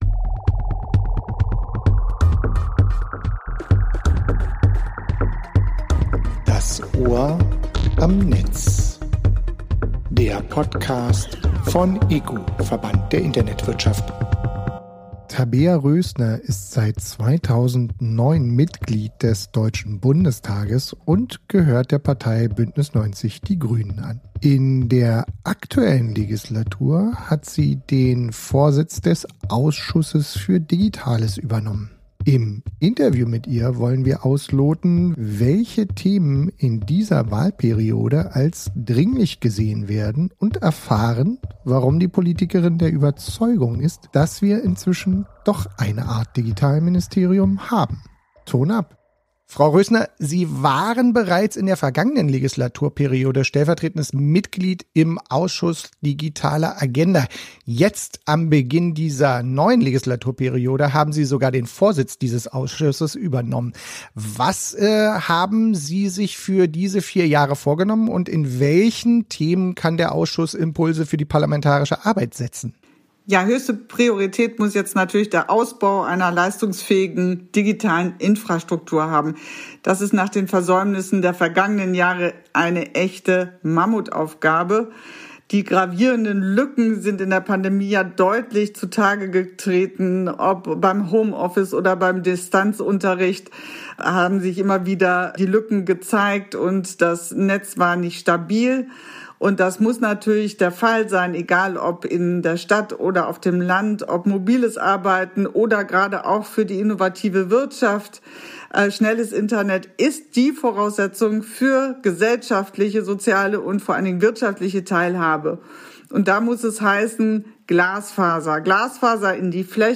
Tabea Rößner im Podcast: Mit der Politikerin sprechen wir bei das Ohr am Netz über nachhaltige Digitalisierung die aktuelle Digitalpolitik.